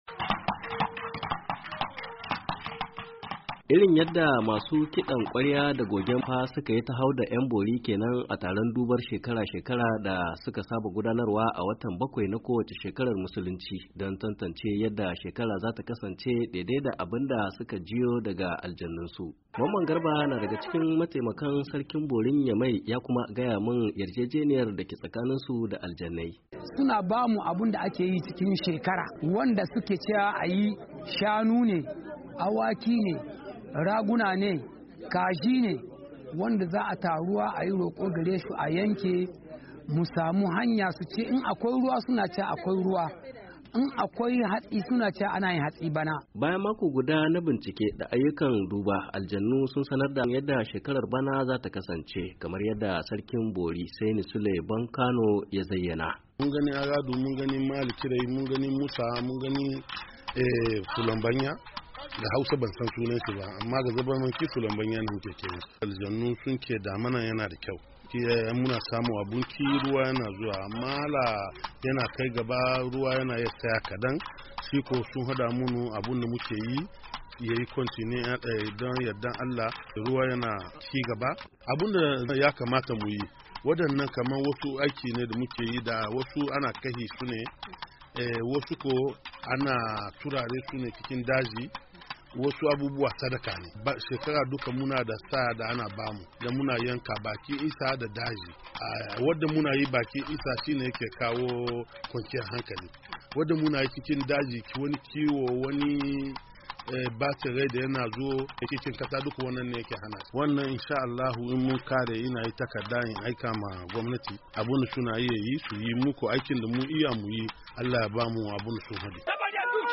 cikkken rahoton: